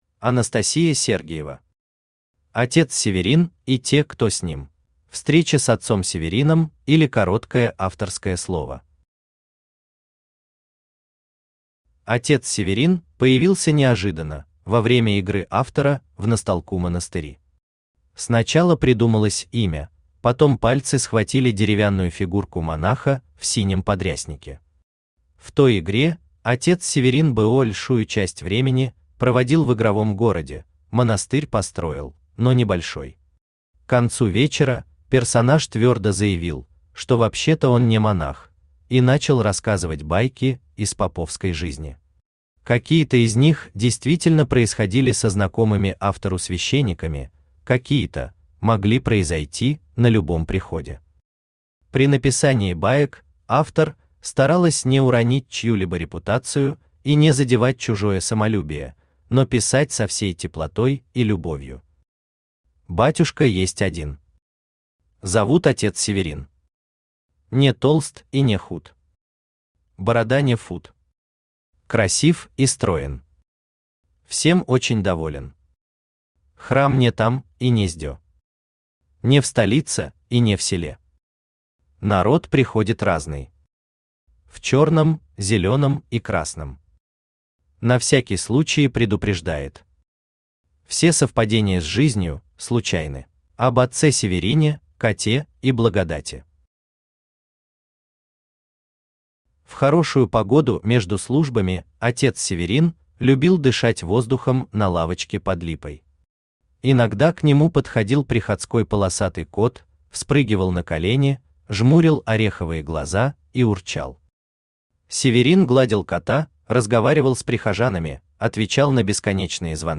Aудиокнига Отец Северин и те, кто с ним Автор Анастасия Сергиева Читает аудиокнигу Авточтец ЛитРес.